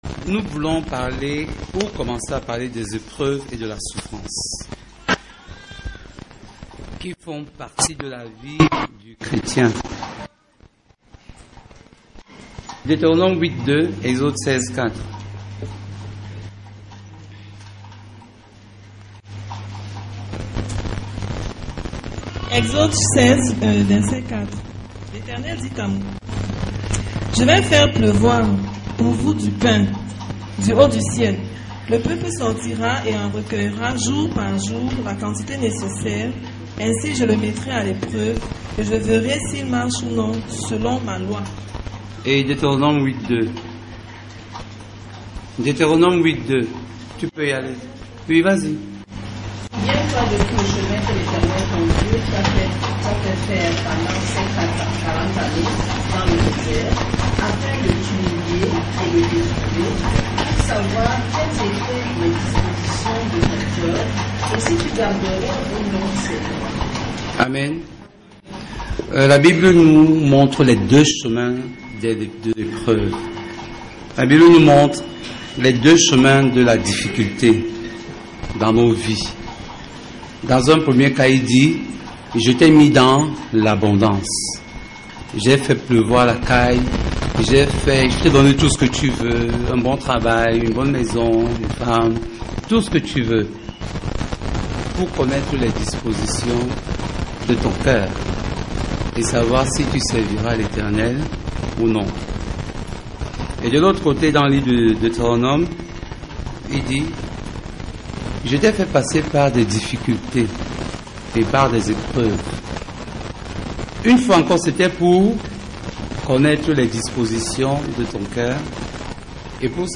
Enseignement sur les épreuves et la souffrance dans la vie du Chrétien.